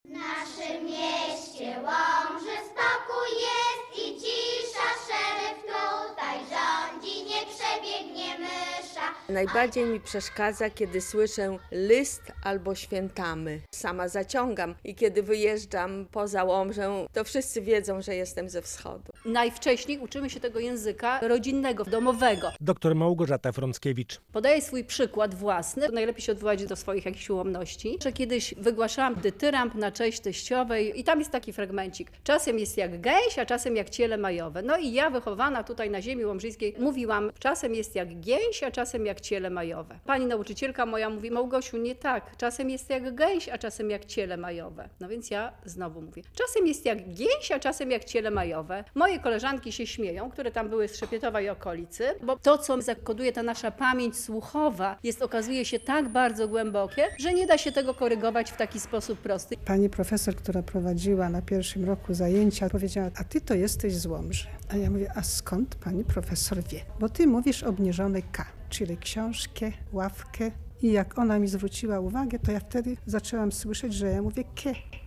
"Mowa codzienna mieszkańców ziemi łomżyńskiej a współczesny język polski” - wykład w Łomży